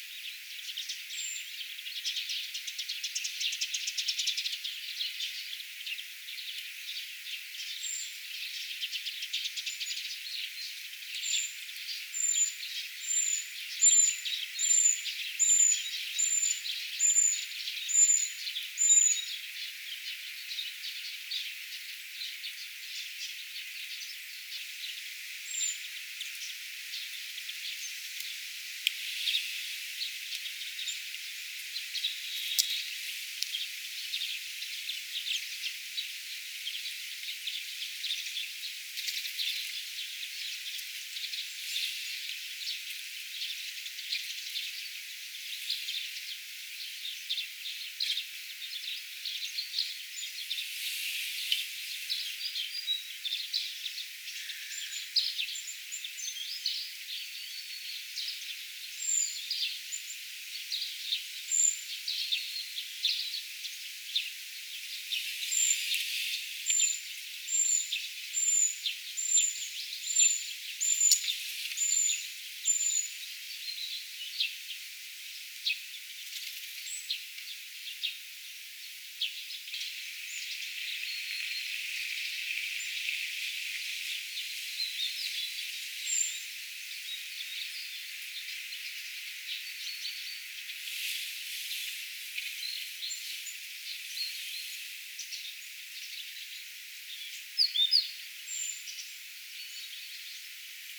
tuolla tavoin ääntelevä puukiipijälintu
tuolla_tavoin_aanteleva_puukiipijalintu.mp3